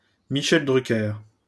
Prononciation du nom